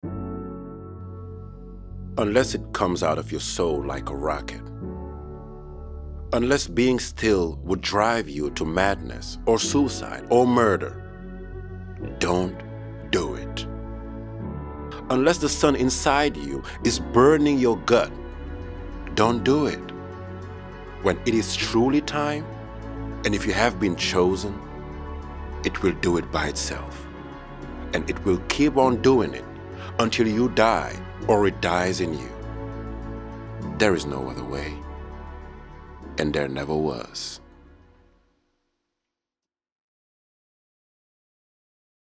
Voix-off
20 - 40 ans - Baryton-basse